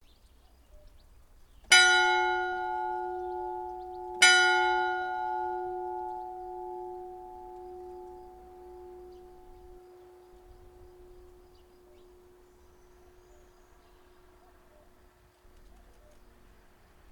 bell church clock ding dong field-recording flickr strike sound effect free sound royalty free Sound Effects